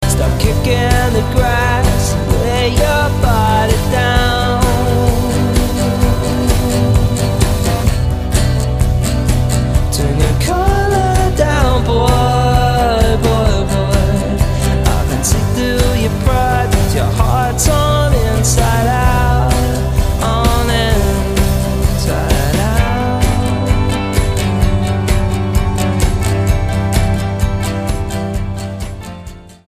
STYLE: Roots/Acoustic
mandolin
guitar strum